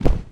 fastroping_thud.ogg